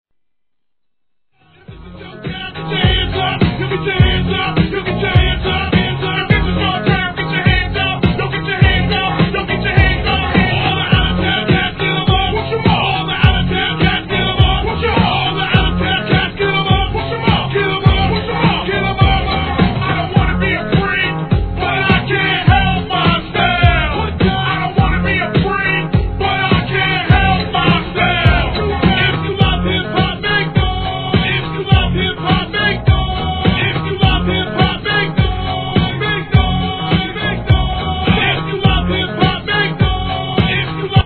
DANCEHALL REMIX
HIP HOP/R&B